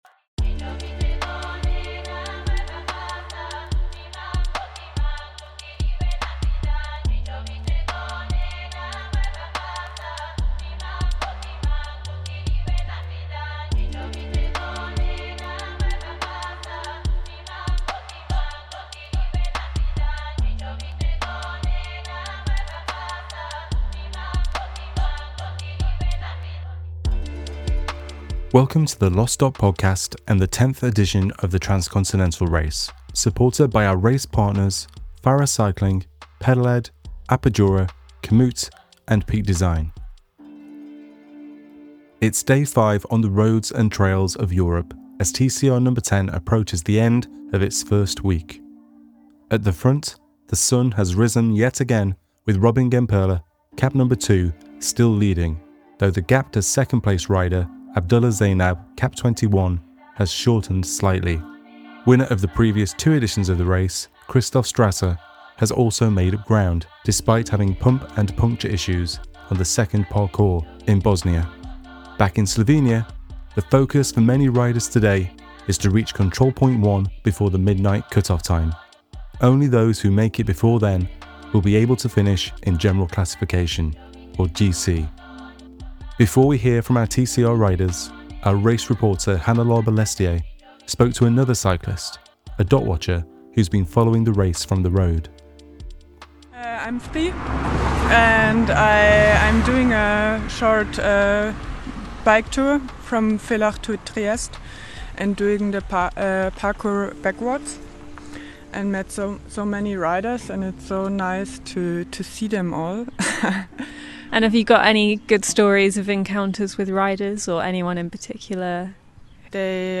interviews riders as they tackle the Mangart Saddle on Parcours 1. Ahead of the closure of Control Point 1 in Slovenia, we hear from riders who are relieved to have made the cut off